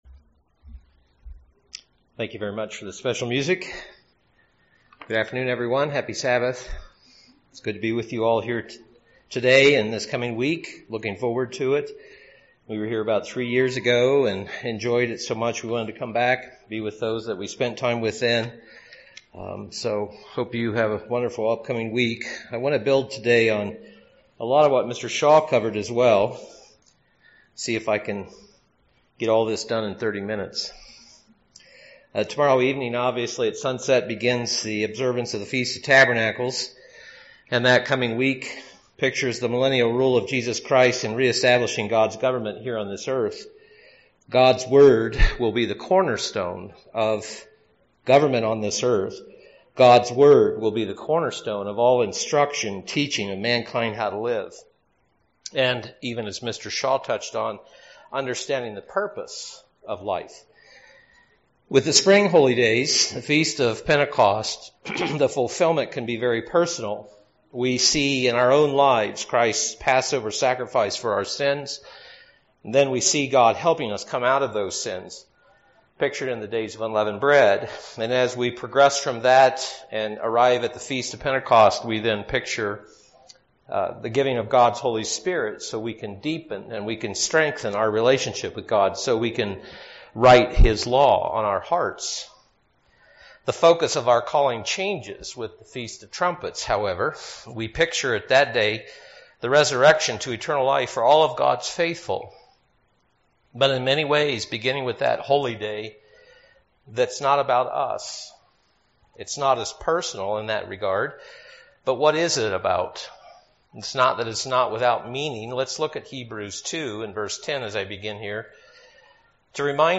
This sermon was given at the Steamboat Springs, Colorado 2019 Feast site.